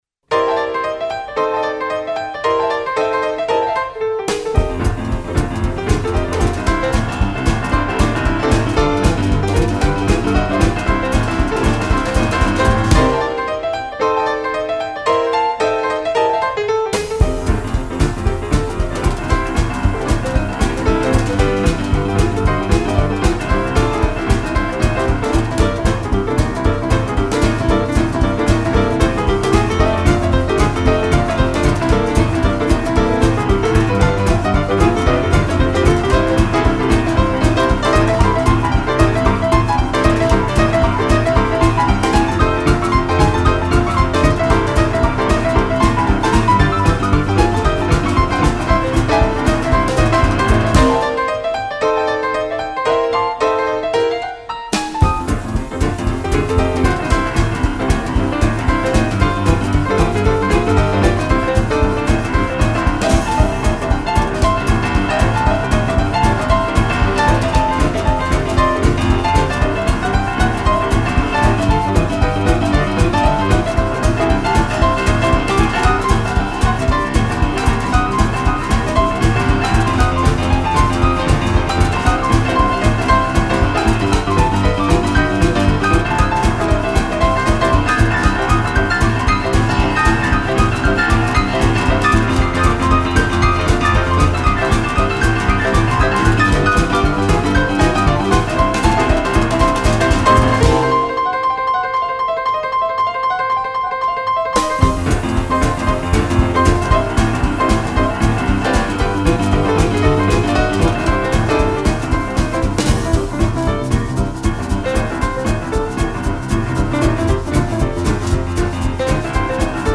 Cd contains heavy-shakin` breathtakin' boogie songs!
grand pianos